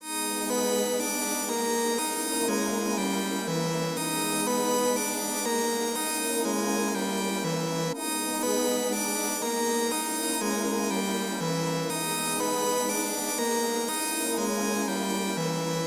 描述：一首由吉他合唱团制作的简短的器乐歌曲。
Tag: 吉他 电动 贝斯 大气 实验 环境